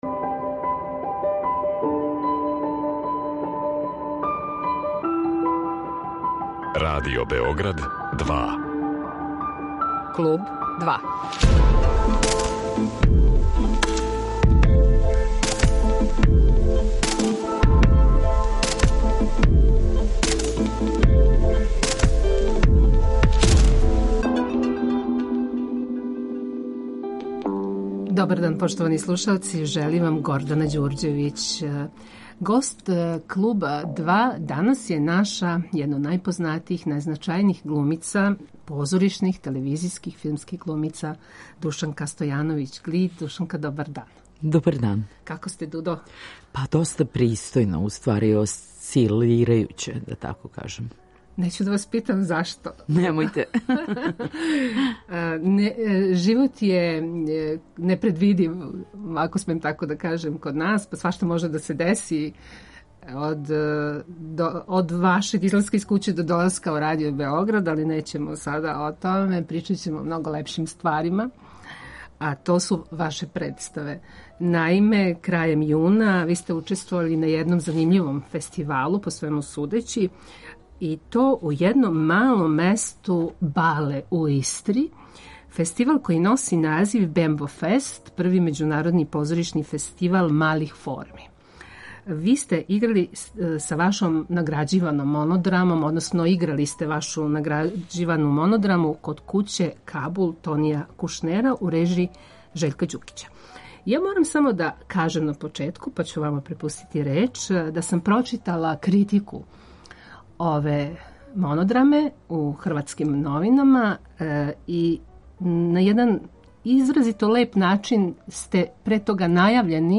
Гост Kлуба 2 је позоришна, телевизијска и филмска глумица Душанка Стојановић Глид.